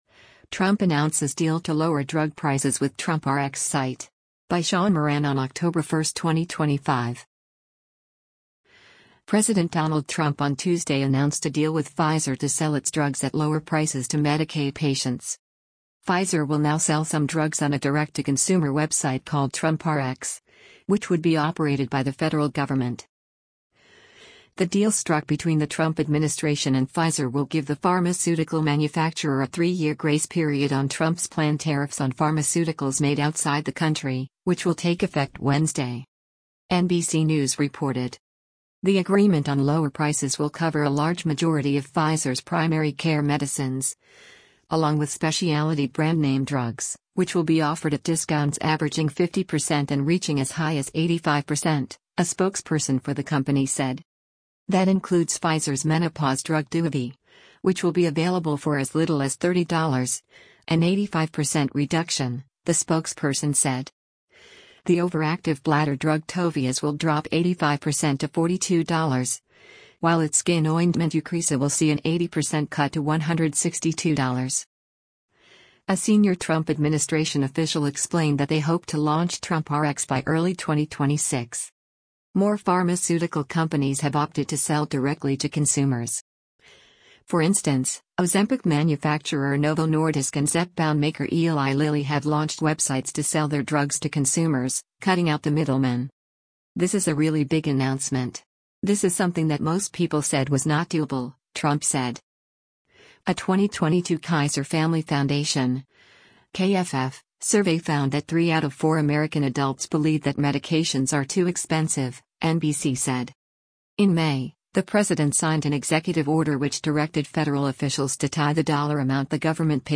U.S. President Donald Trump speaks to the media in the Oval Office at the White House on S